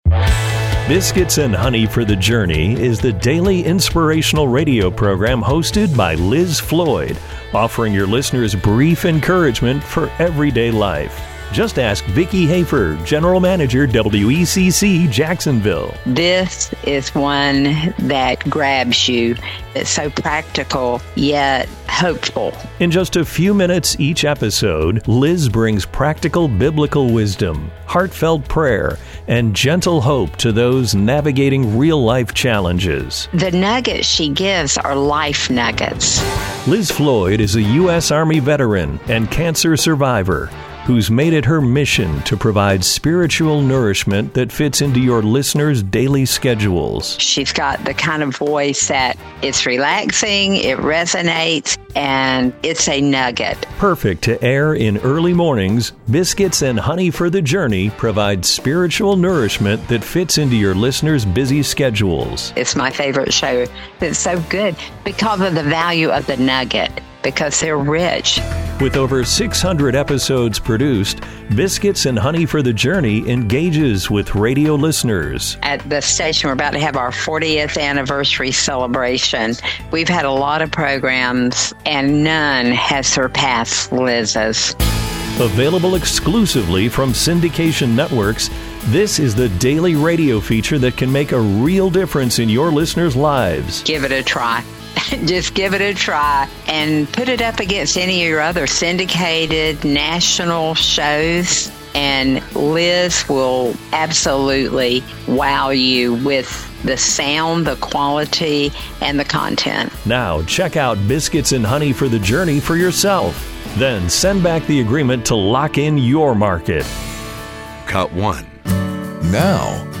* It's the daily radio feature that can make a real difference in your listener's lives!